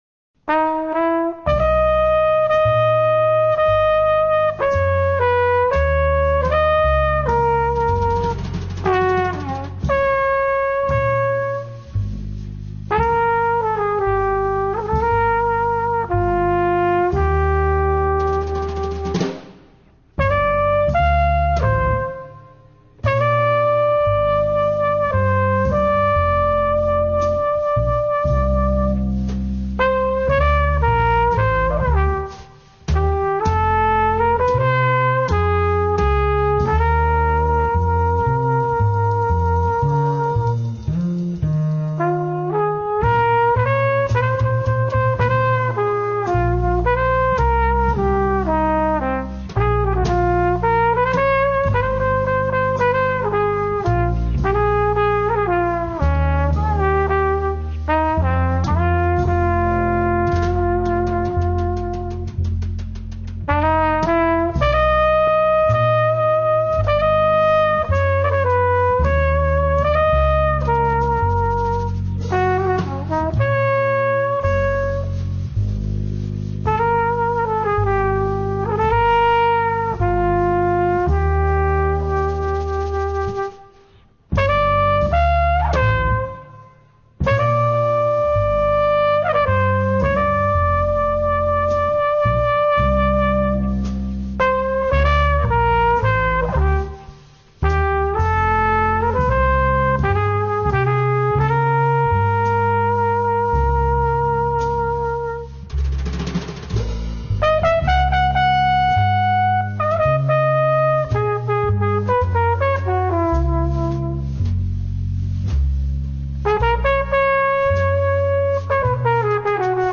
Trumpet
Bass
Drums